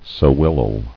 [se·wel·lel]